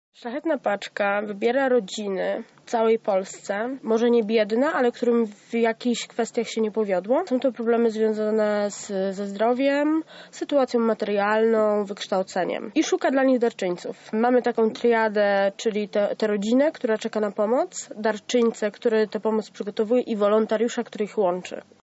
wolontariuszka